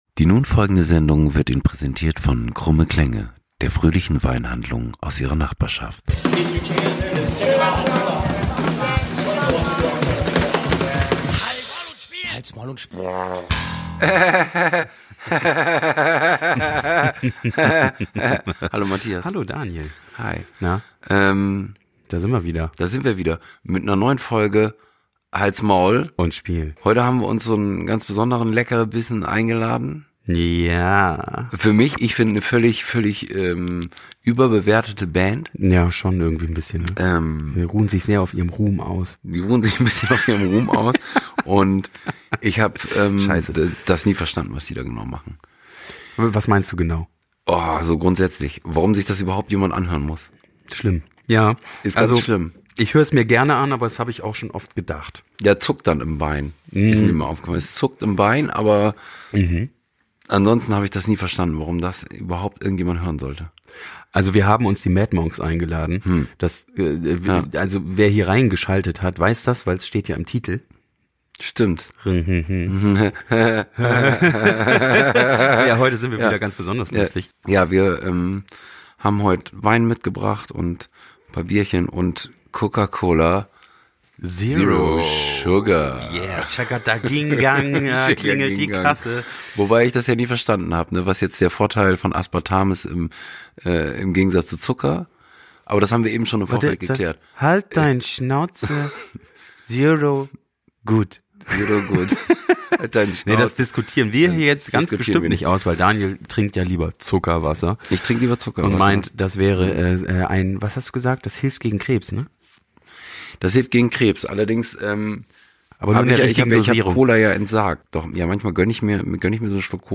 November 2022 Nächste Episode download Beschreibung Teilen Abonnieren Heute mit: MAD MONKS (Skapunk) aus Bremen Was ist diesmal los?